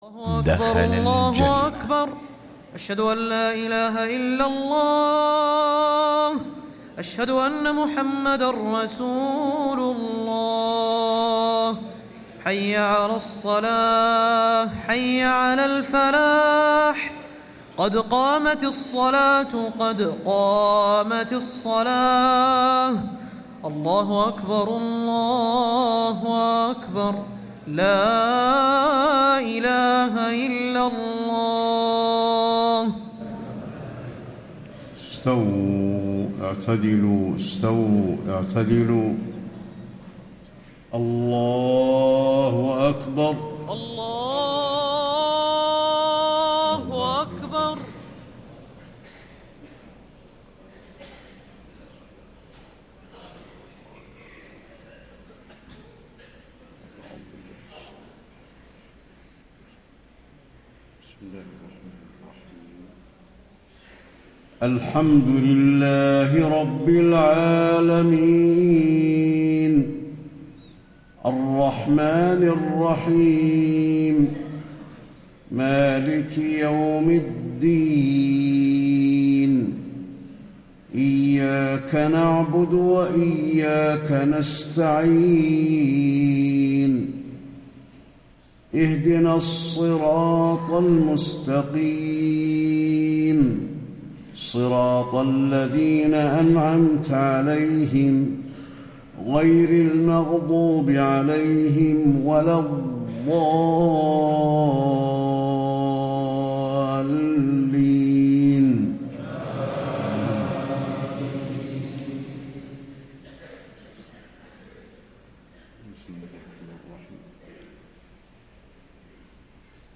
صلاة المغرب الجمعة 5 - 1 - 1435هـ سورتي الفلق و الناس > 1435 🕌 > الفروض - تلاوات الحرمين